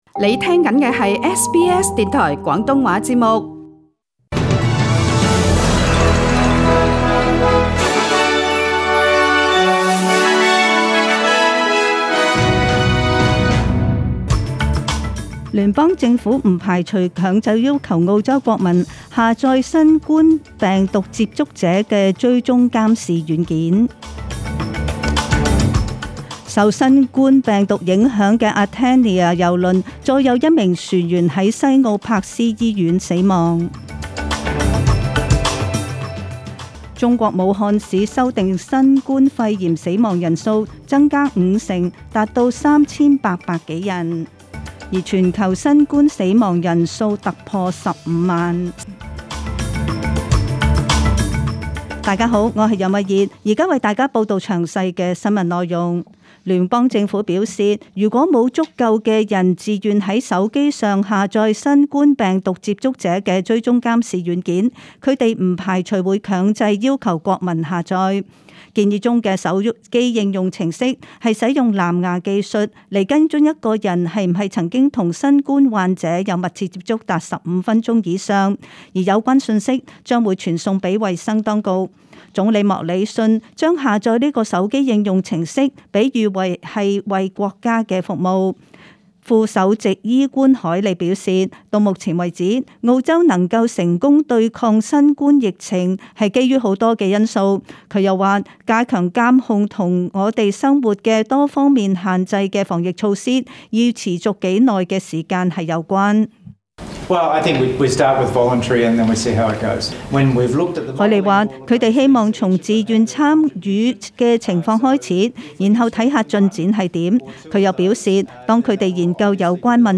SBS 中文新聞 （四月十八日）
SBS 廣東話節目中文新聞 Source: SBS Cantonese